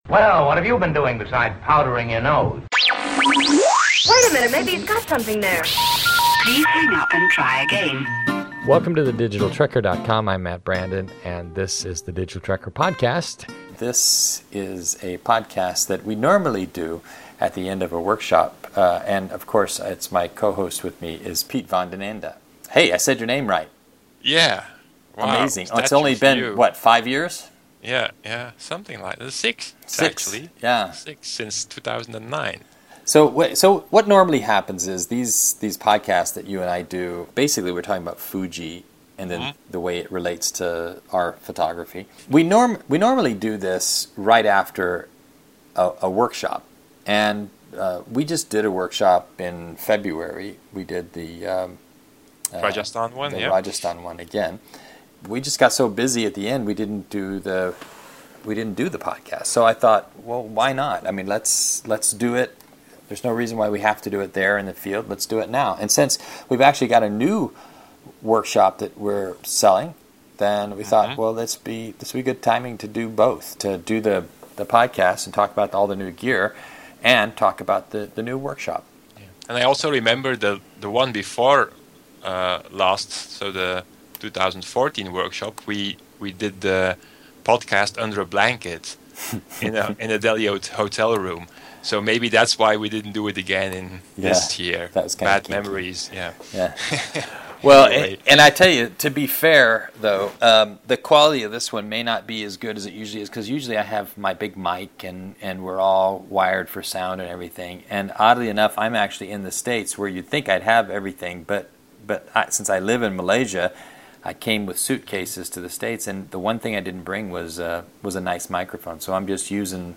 Because we do it in the field it sometimes becomes difficult to find a good location to record these discussions. It is India after all, things are noisy.